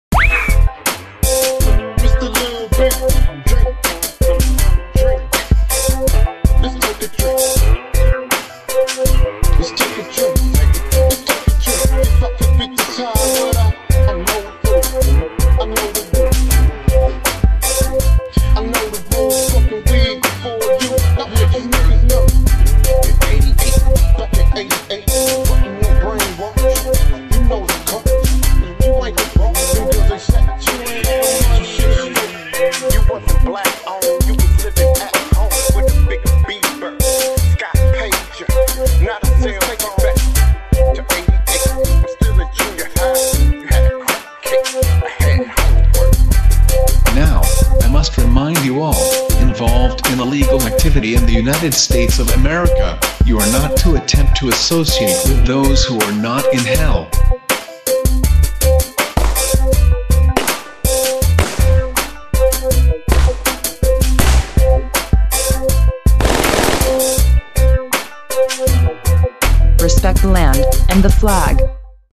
dance/electronic
RnB
Rap
Hip-hop